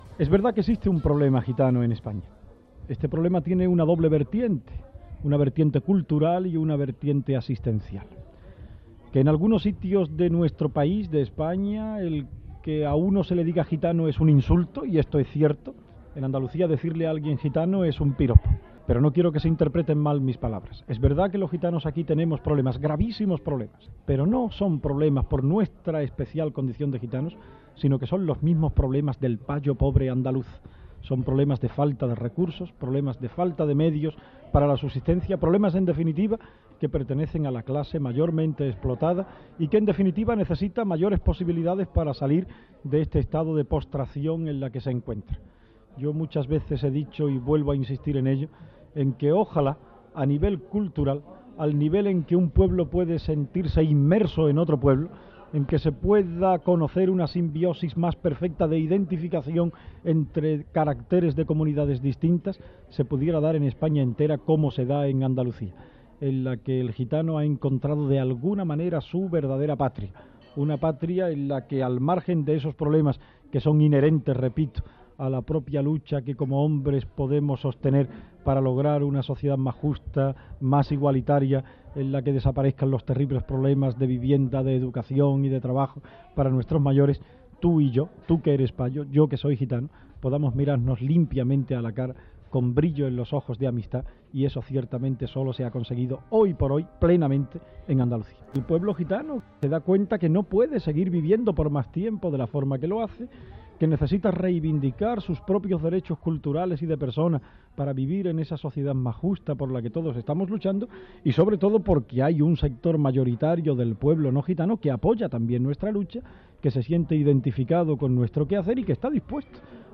Opinió del diputat Juan de Dios Ramérez Heredia sobre la stuació dels gitanos a la societat andalusa